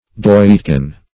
Doitkin \Doit"kin\ (doit"k[i^]n), n. A very small coin; a doit.